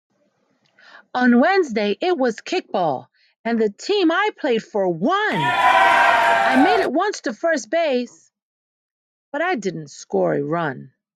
Story telling from enthusiastic teachers.